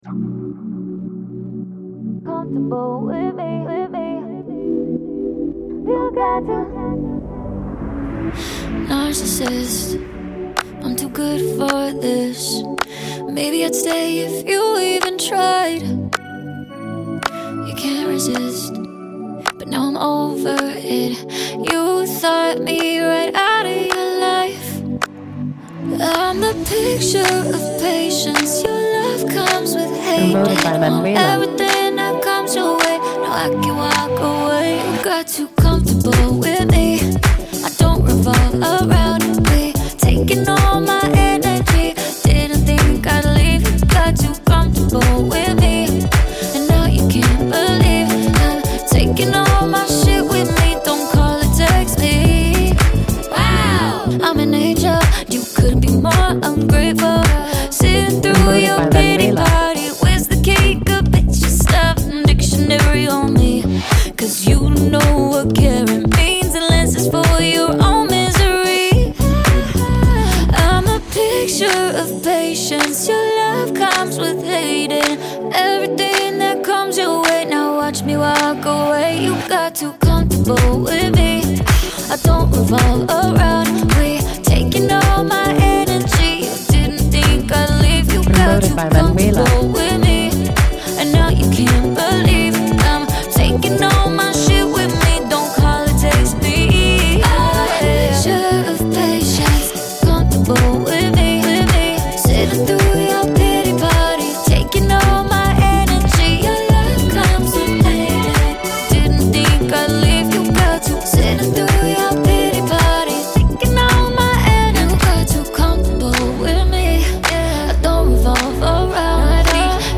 Jeune chanteuse et compositrice de musique franco-américaine
Radio Edit